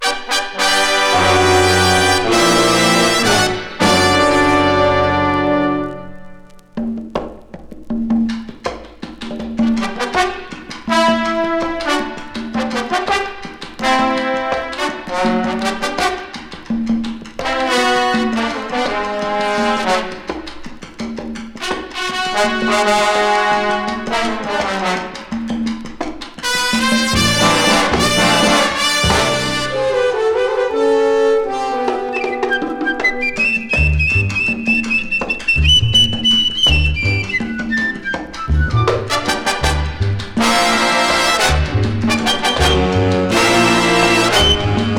Jazz, Big Band　USA　12inchレコード　33rpm　Mono